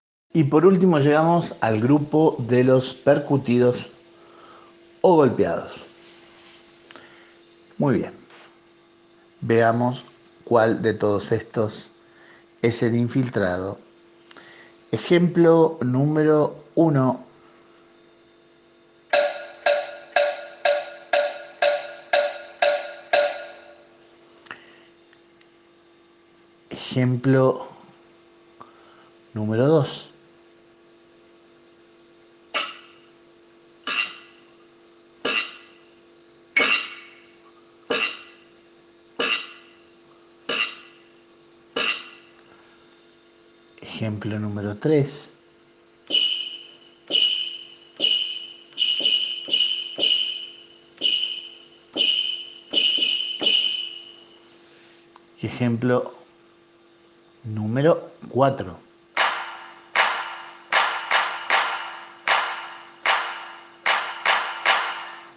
Y por último los percutidos o golpeados veamos como te va con ellos
Grupo Percutidos.mp3